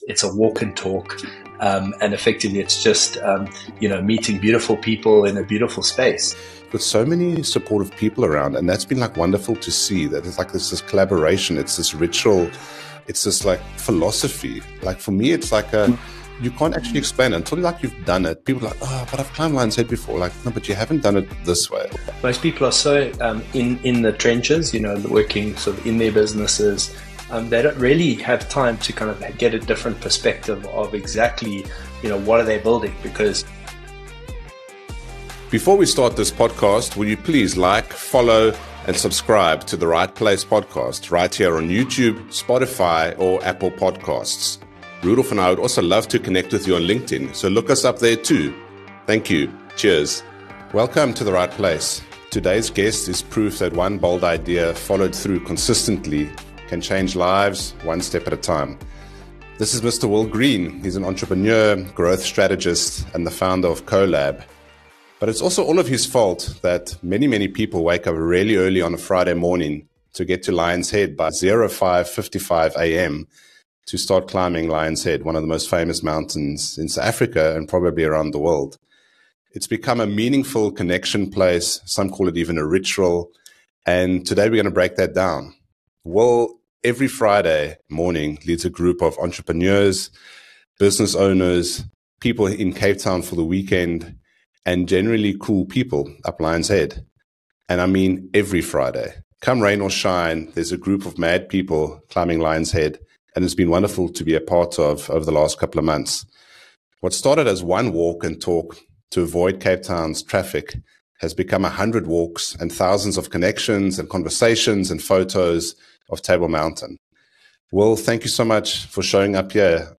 The conversation explores the mission of the network, the various activities it offers, and the importance of building relationships through networking.